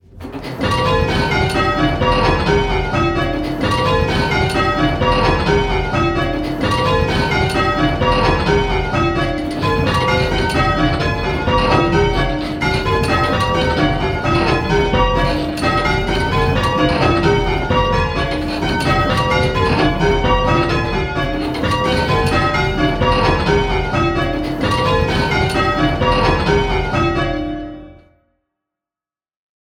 Try and pick out the treble and hear the different speeds it rings at.
1. All bells equal...
This version is clean… all the bells can be heard at the same level and therefore picking out the treble is hard.